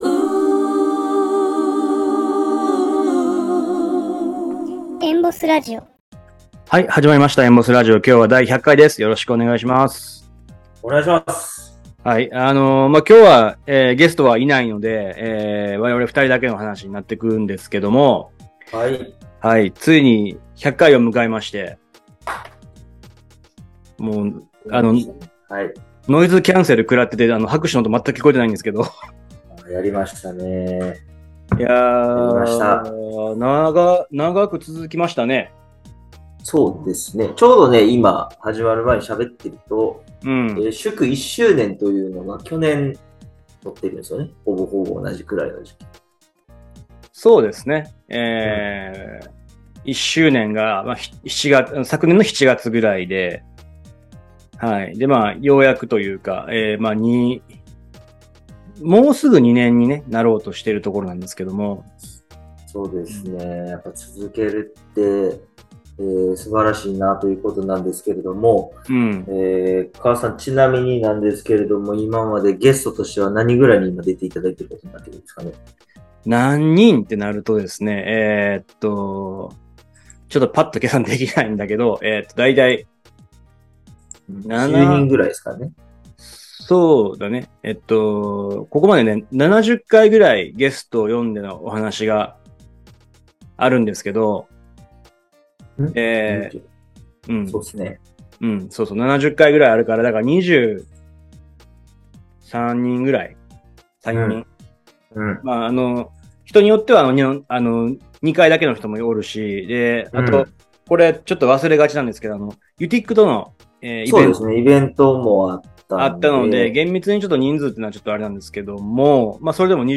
#100 embossRadioがついに100回を迎えました！筑波大学のOBOGをつなぐ超ローカルなラジオの振り返りと今後の展望をMC二人で語る